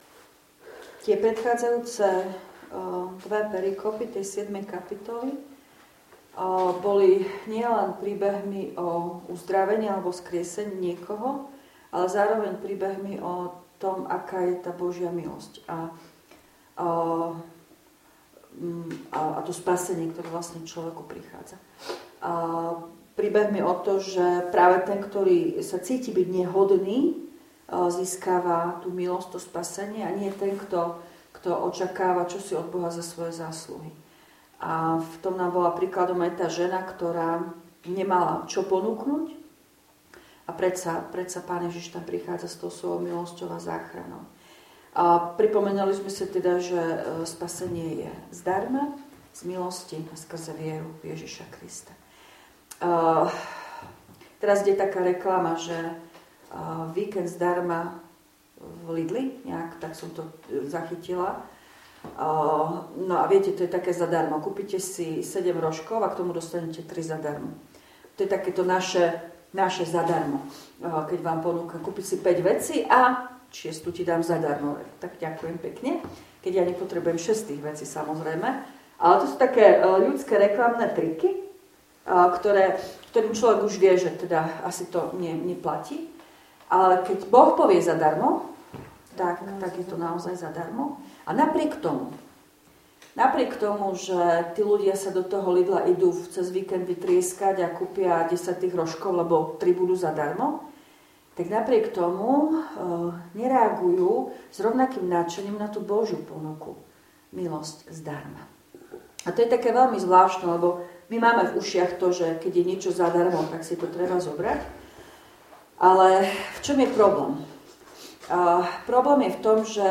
V nasledovnom článku si môžete vypočuť zvukový záznam z biblickej hodiny zo dňa 25.2.2025.